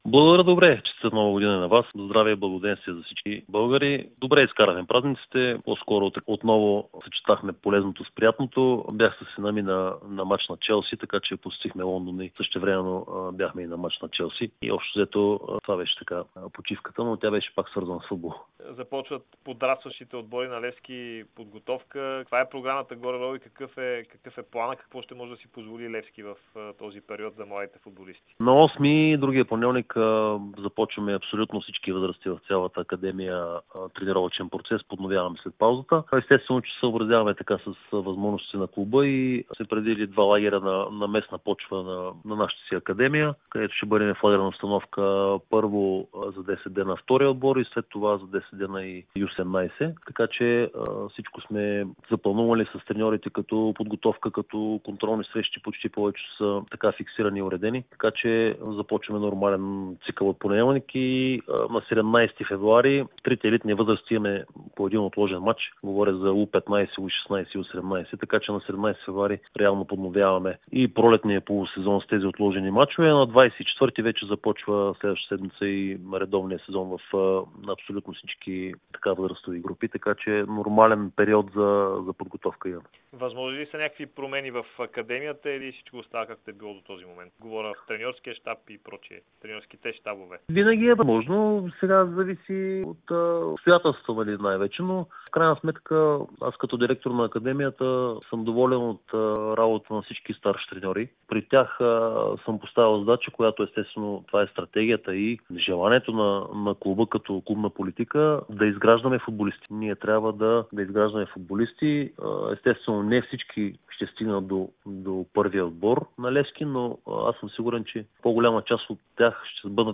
Директорът на школата на Левски Елин Топузаков даде обширно интервю пред Дарик и dsport, в което разкри кога започват подготовка отборите в академията на "сините", какви са целите в школата и клубната политика за втория тим на Левски, който се състезава в Трета лига.